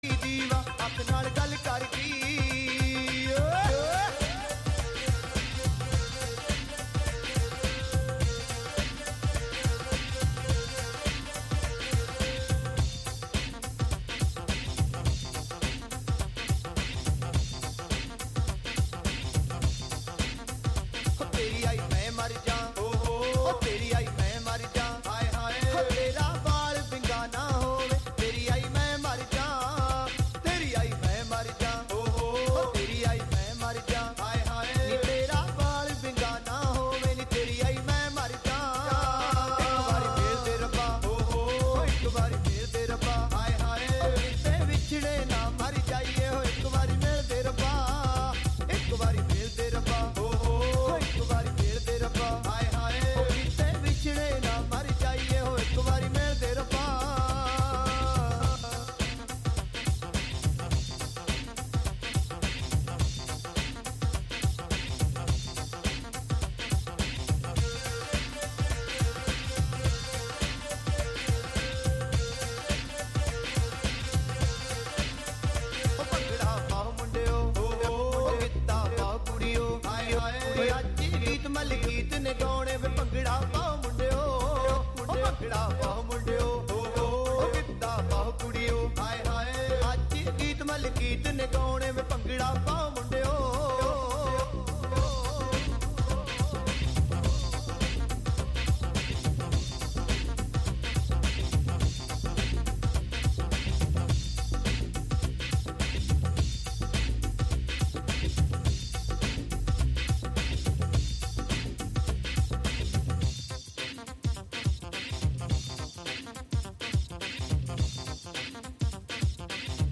Show includes local WGXC news at beginning and midway through.